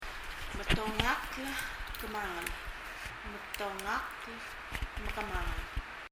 metongakl / kemangel [mɛtɔŋʌkl / kəməŋɛl]